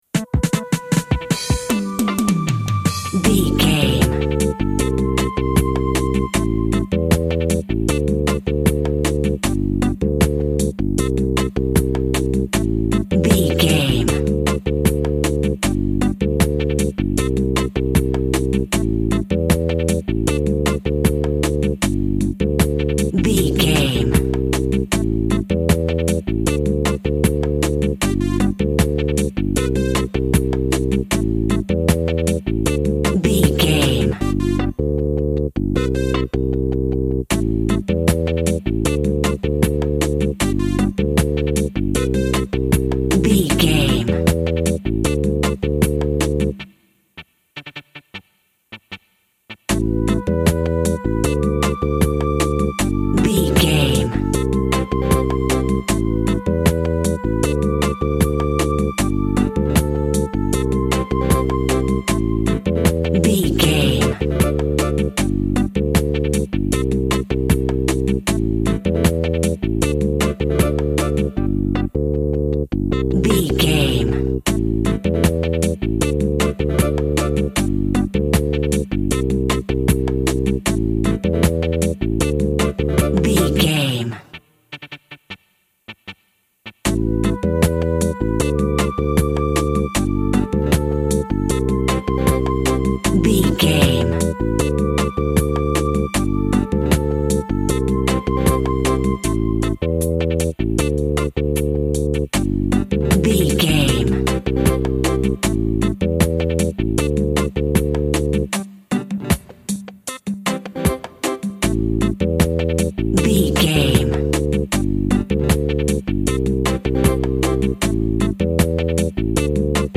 Also with small elements of Dub and Rasta music.
Uplifting
Ionian/Major
reggae
drums
bass
guitar
piano
brass
steel drum